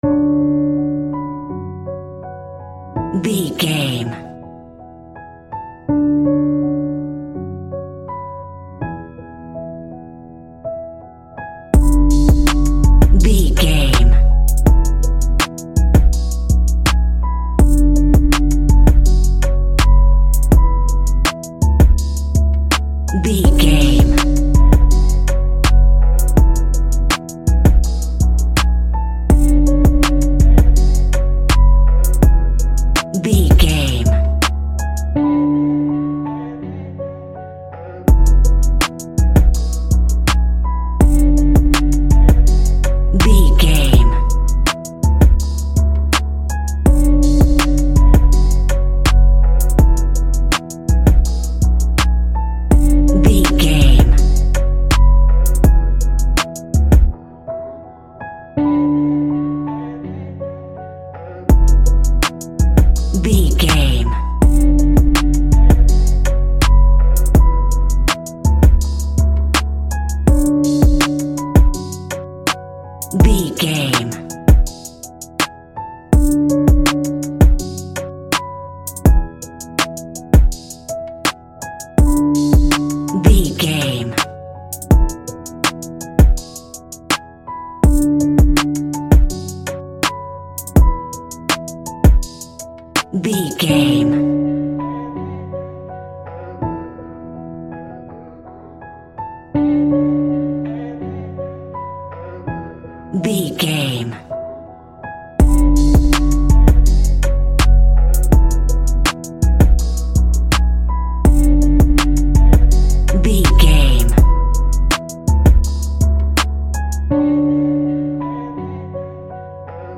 Ionian/Major
drums
smooth
calm
mellow